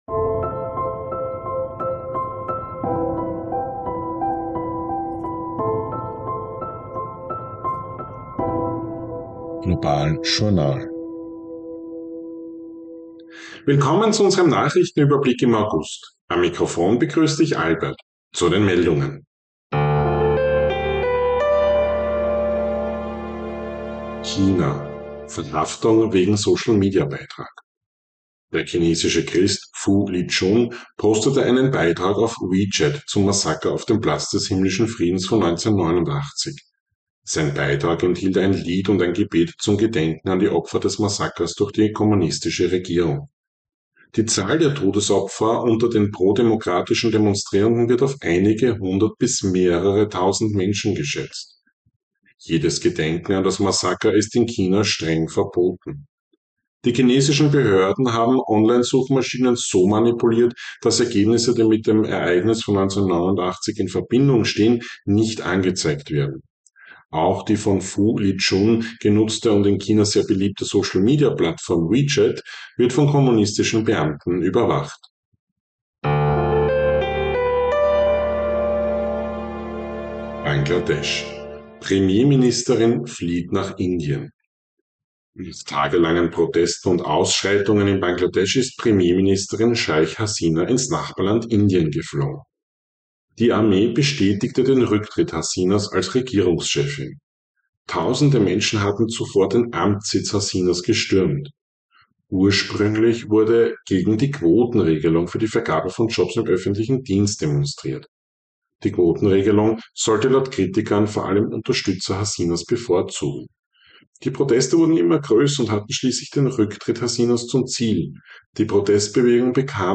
News Update August 2024